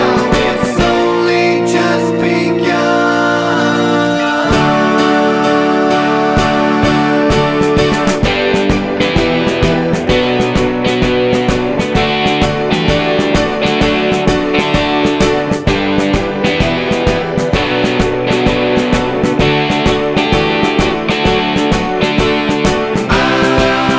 Down Four Semitones Glam Rock 3:45 Buy £1.50